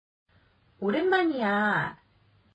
ネイティブの発音を沢山聞いて正しい読み方を覚えましょう。
오랜만이야 [オレンマニヤ]